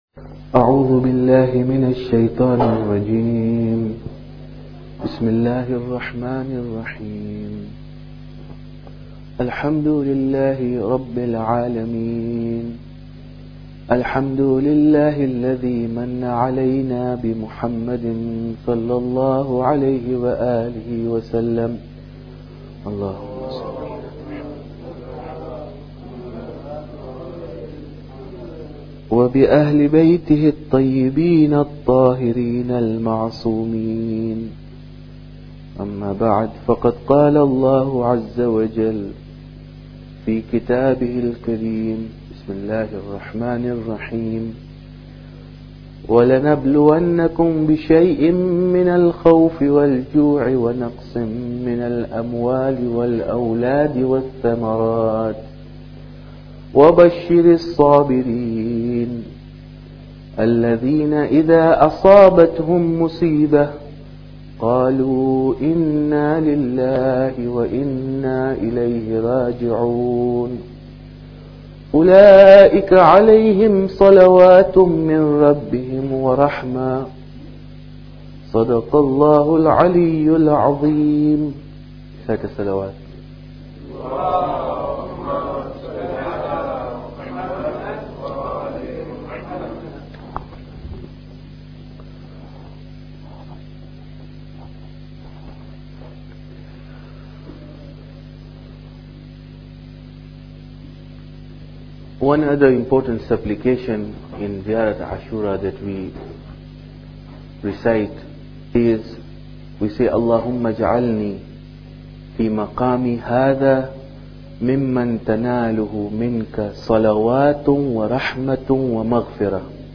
Muharram Lecture Sham Ghariban 12